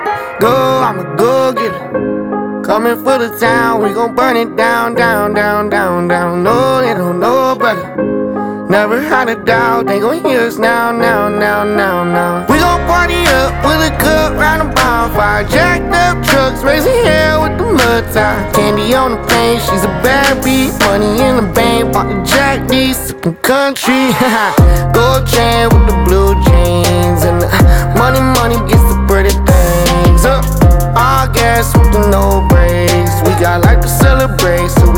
2025-05-23 Жанр: Кантри Длительность